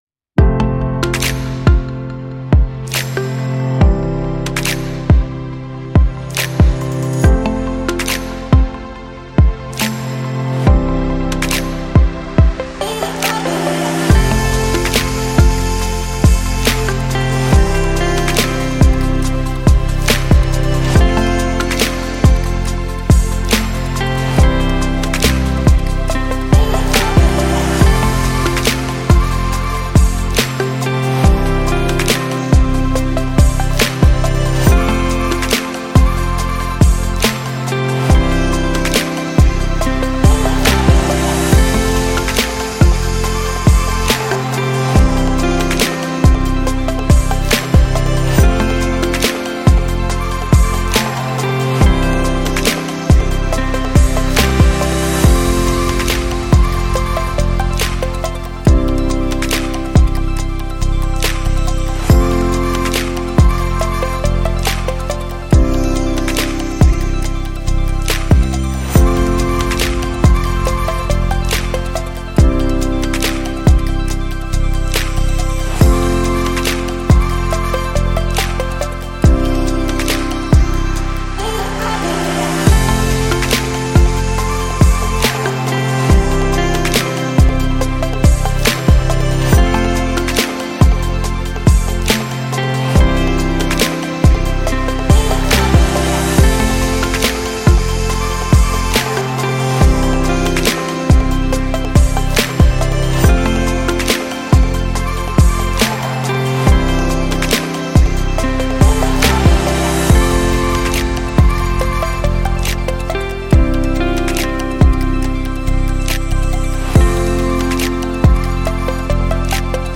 9 - Soft Lounge Hip-Hop